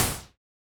Index of /musicradar/retro-drum-machine-samples/Drums Hits/Raw
RDM_Raw_SY1-Snr01.wav